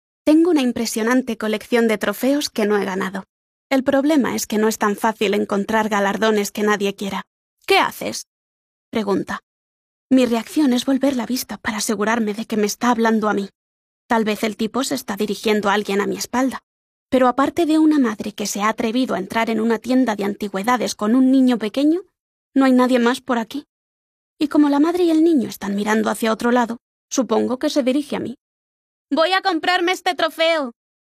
une voix off espagnole lumineuse et captivante – aiguë, vivante et polyvalente – idéale pour les publicités, l'e-learning et la narration.
Livres audio
Microphone : Neumann TLM 102.